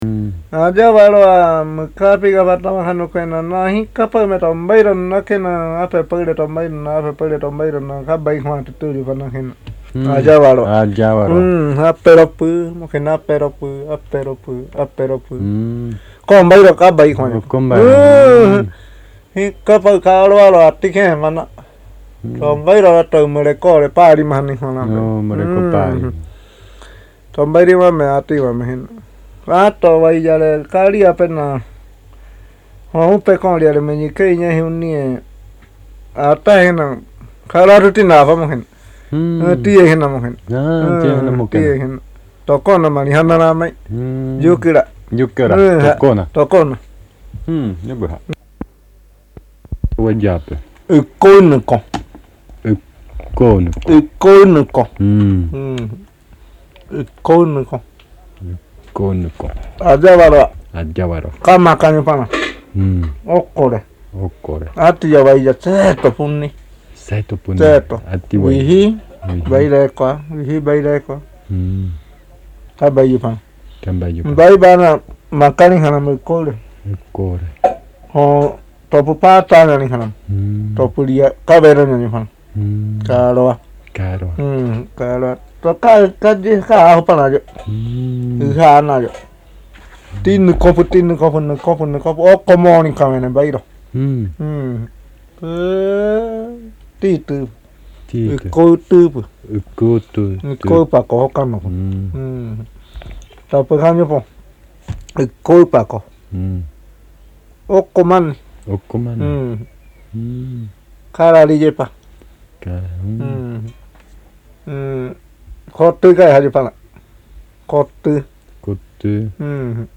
Relato del Kumú (Curador de mundo)
sobre los mitos de origen del pueblo Tatuyo, grabado en su maloca